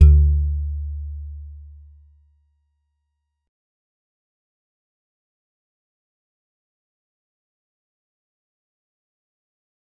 G_Musicbox-D2-mf.wav